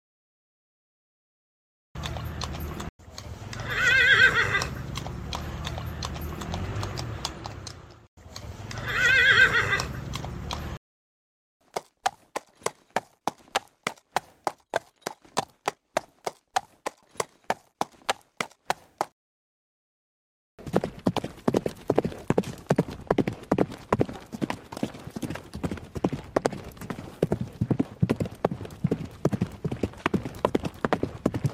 每一种步伐，都是大地与生命共同奏出的节拍。
马匹步伐声.mp3